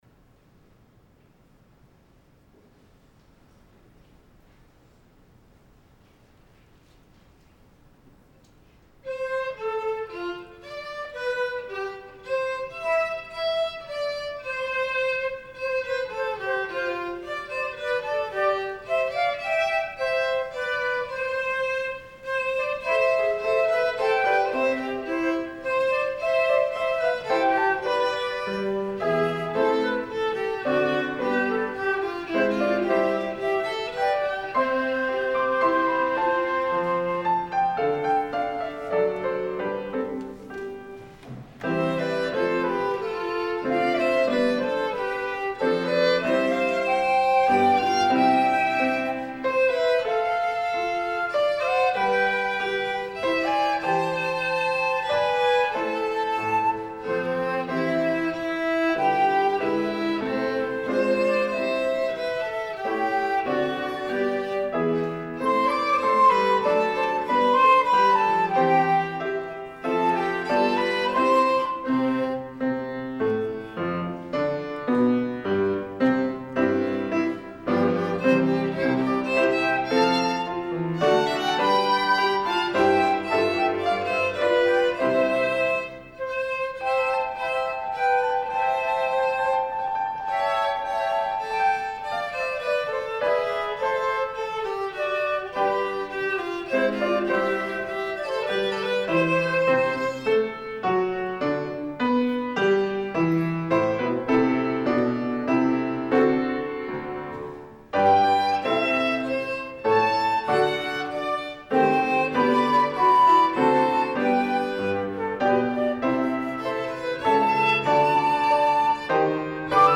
Audio only for Sunday Worship 10-18-20